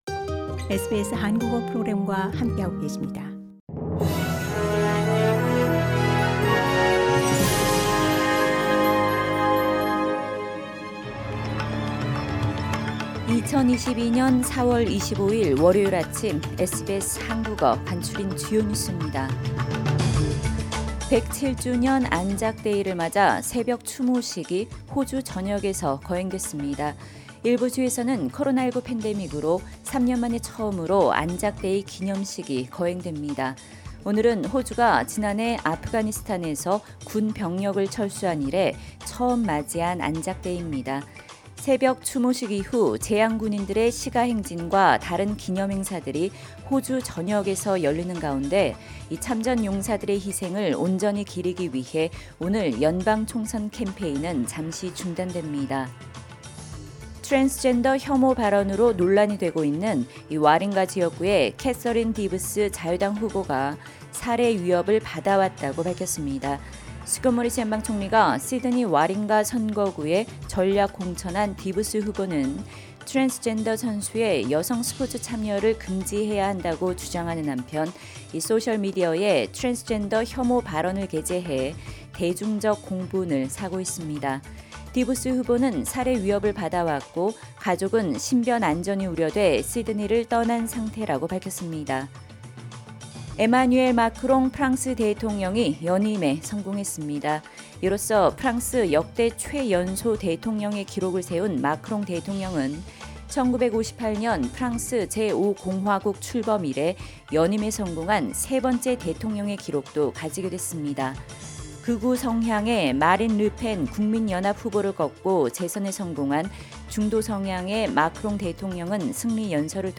SBS 한국어 아침 뉴스: 2022년 4월 25일 월요일